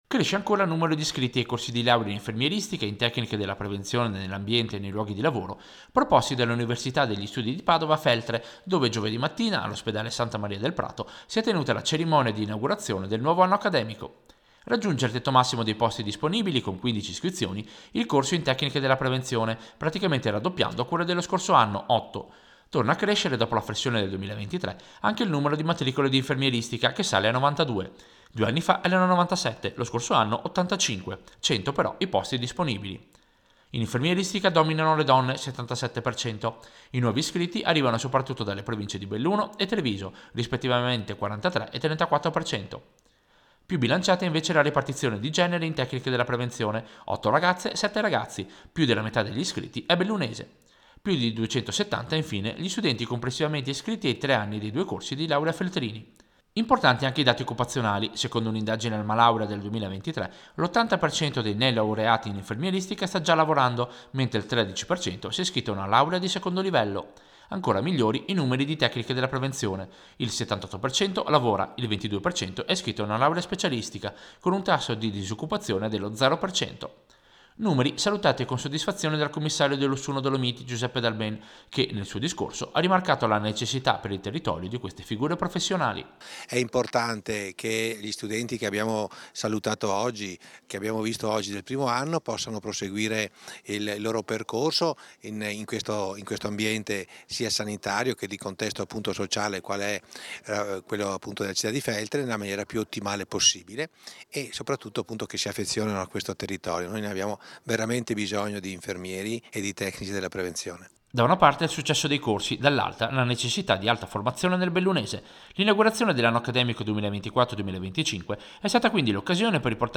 Servizio-Inaugurazione-anno-accademico-Feltre-2024.mp3